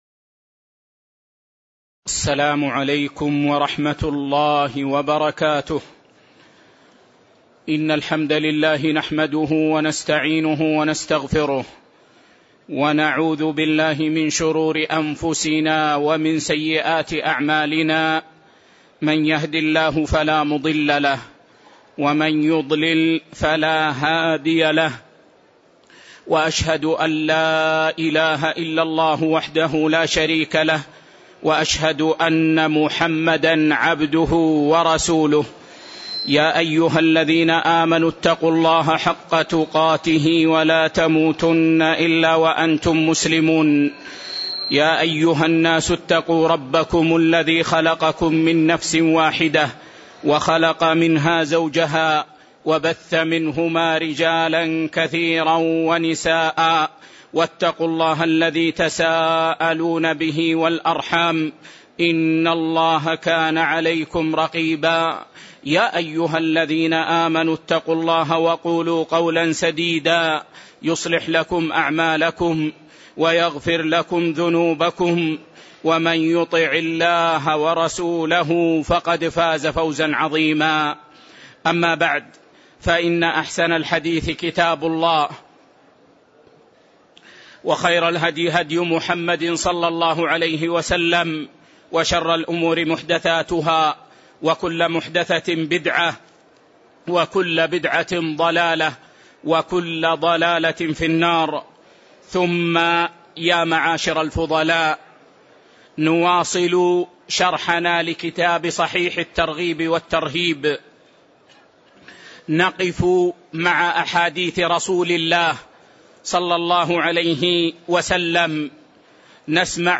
تاريخ النشر ٢١ ربيع الأول ١٤٣٨ هـ المكان: المسجد النبوي الشيخ